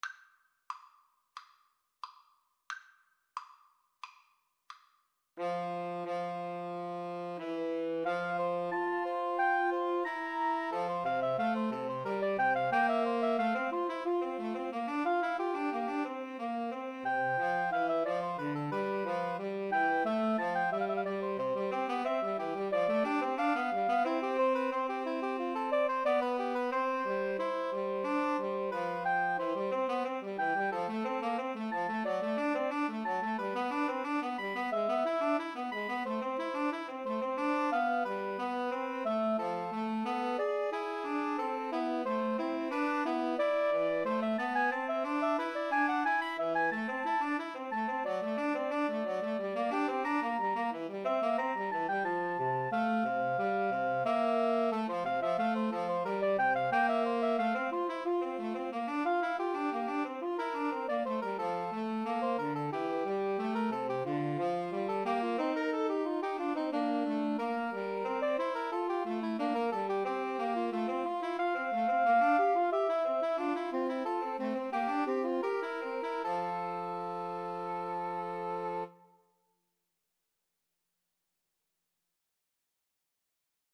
Play (or use space bar on your keyboard) Pause Music Playalong - Player 1 Accompaniment Playalong - Player 3 Accompaniment reset tempo print settings full screen
F major (Sounding Pitch) (View more F major Music for Woodwind Trio )
Woodwind Trio  (View more Advanced Woodwind Trio Music)
Classical (View more Classical Woodwind Trio Music)